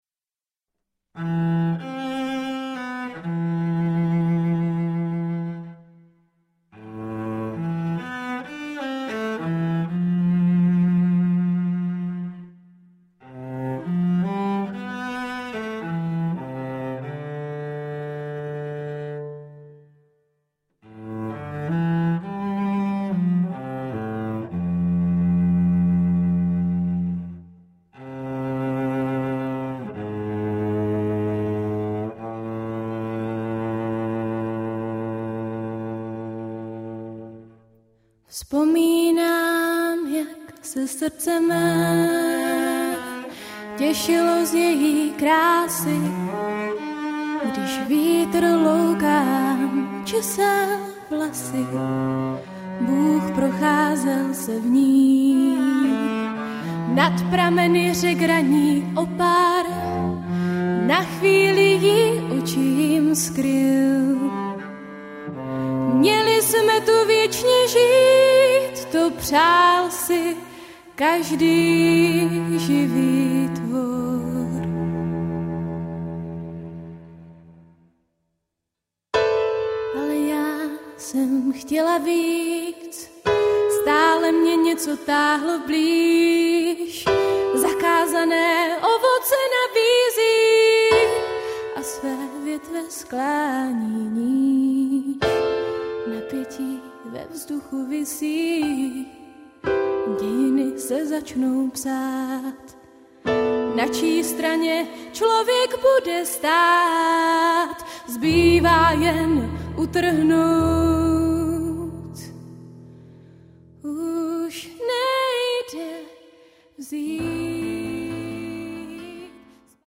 Žánr: jazz, pop.